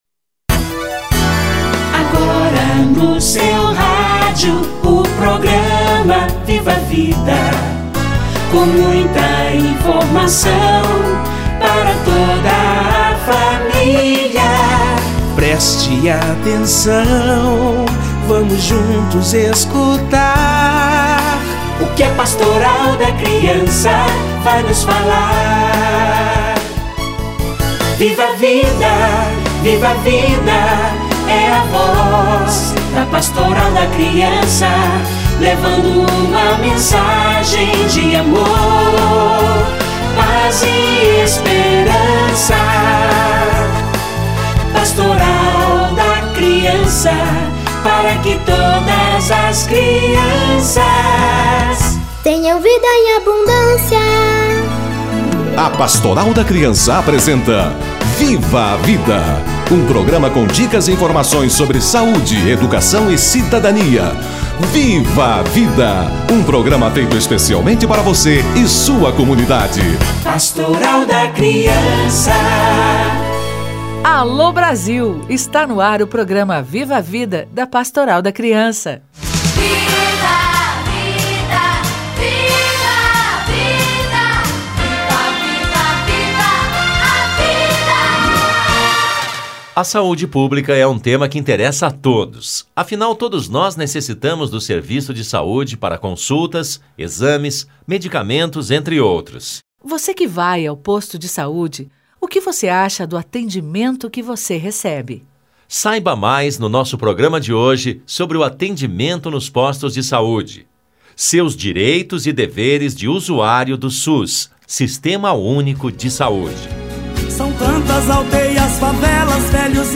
Direito à saúde - Entrevista